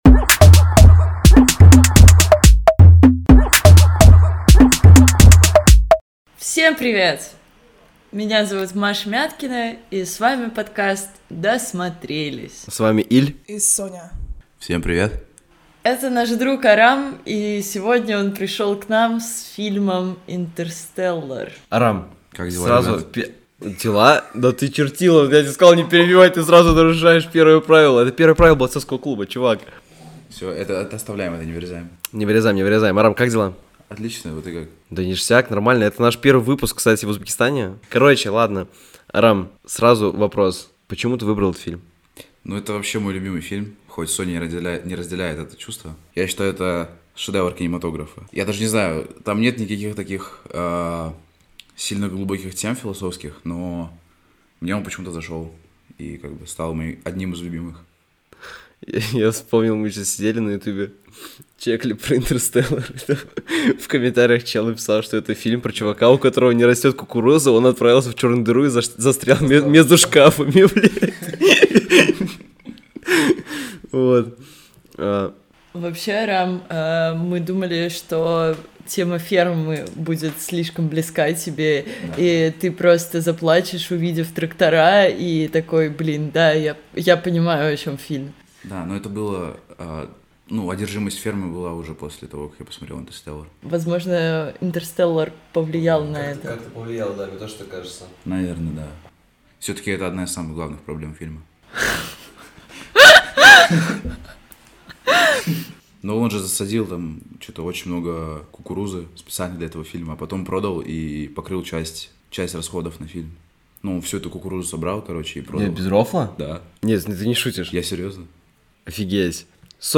Мы – три хороших друга, которые решили записывать подкаст о самом любимом – о кино. Каждый из нас по очереди выбирает фильм и извлекает из него волнующую тему, о которой хочет поговорить, а иногда мы ещё и зовём друзей.